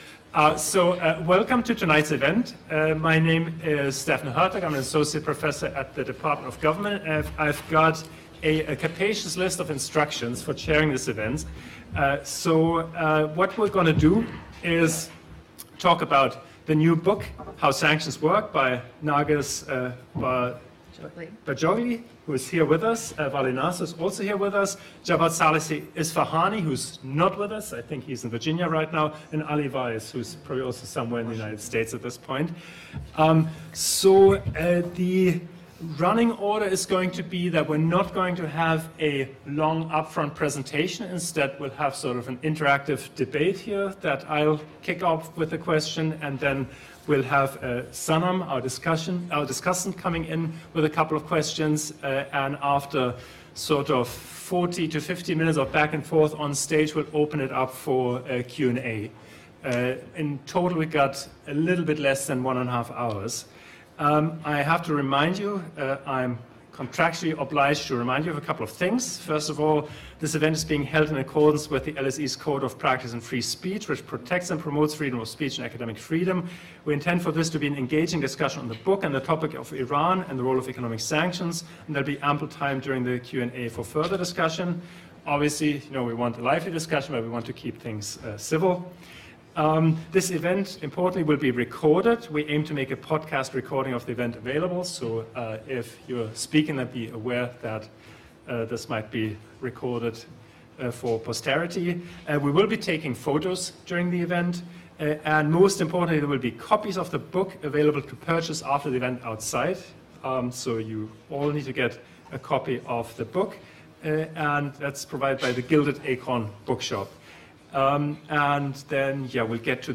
Listen to or download podcasts from our 2024 public events at the Department of International Relations at LSE